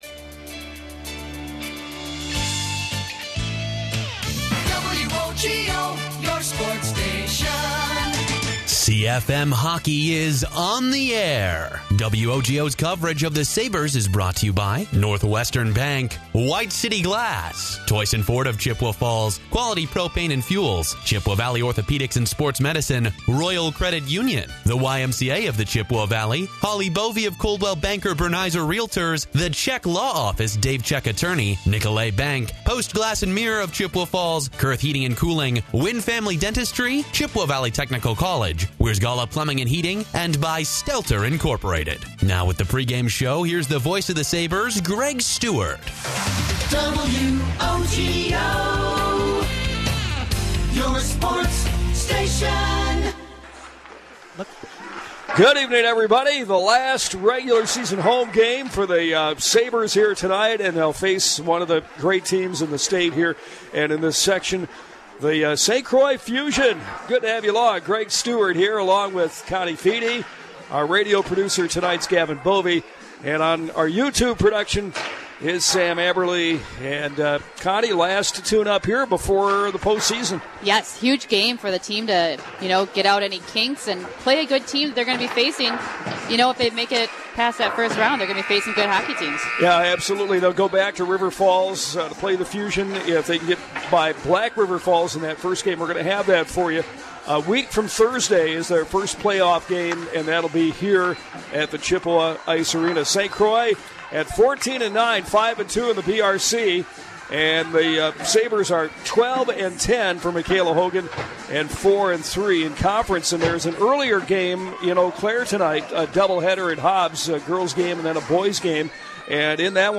the Chippewa Falls/Menomonie Sabres played the St. Croix Fusion at The Chippewa Ice Arena on 2/11/25
Share to X Share to Facebook Share to Pinterest Labels: High School Sports